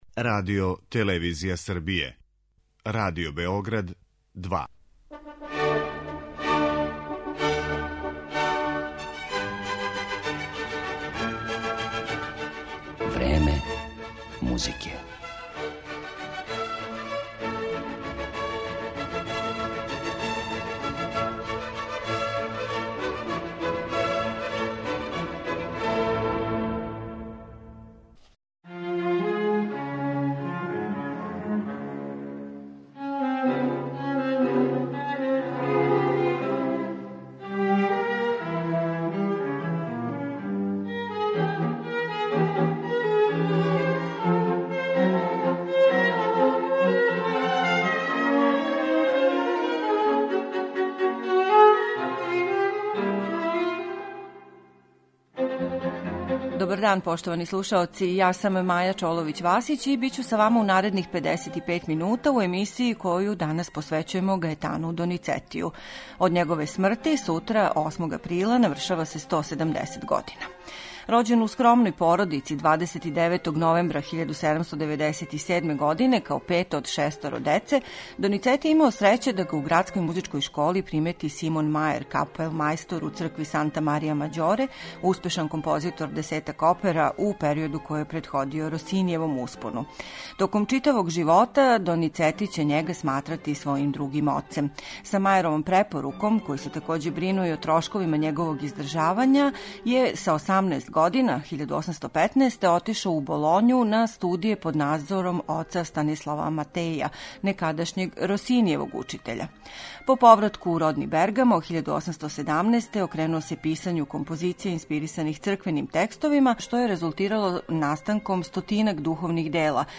У његовом опсу од преко 600 композиција је и седамдесетак опера, међу којима је низ оних које се убрајају у најпопуларнија остварења белканто репертоара. Љубавни напитак, Лучија од Ламермура, Дон Пасквале ... - само су неке од опера чијим ће фрагментима бити предствљено стваралаштво великог италијанског оперског мајстора.